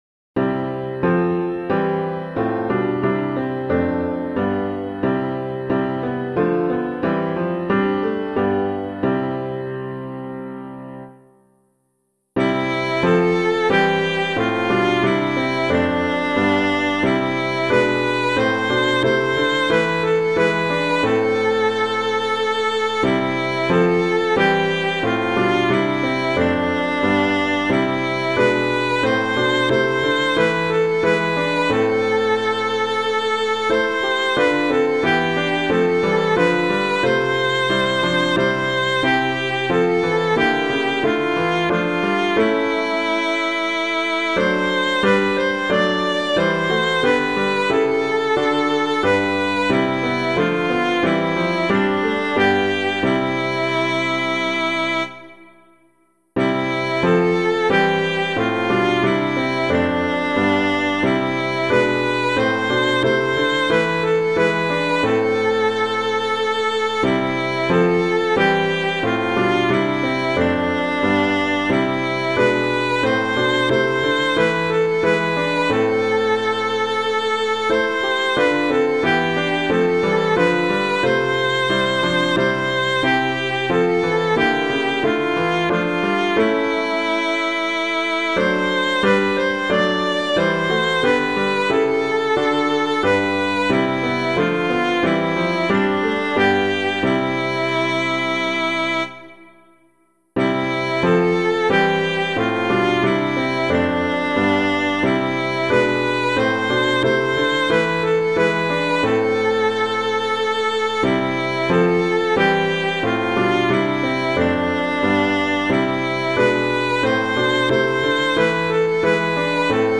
Downloads:   piano
O Sacred Head Surrounded [Baker - PASSION CHORALE] - piano.mp3